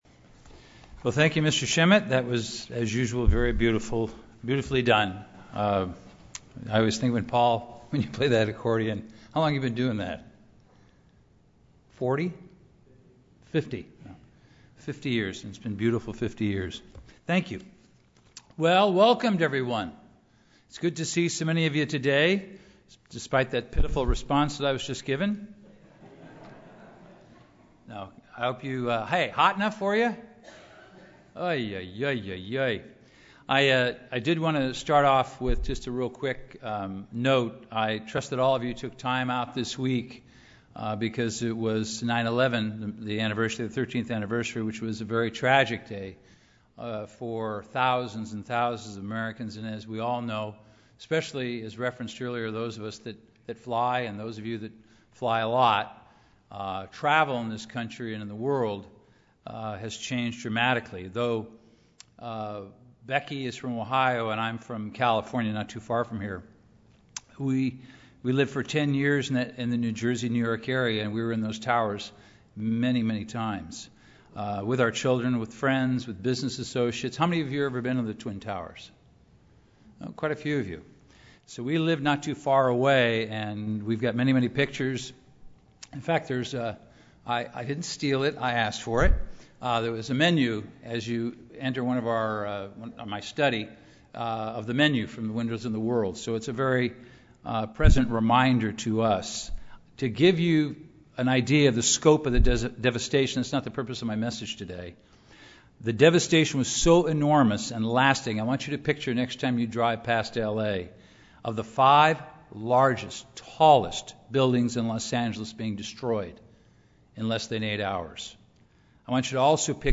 This sermon covers two primary topics: 1. The importance of loving your neighbor and 2. To consider both testaments for a more complete understanding of Biblical principles and how we are to live our lives. Obedience to God involves both duty to love God and duty to love man.
Given in Los Angeles, CA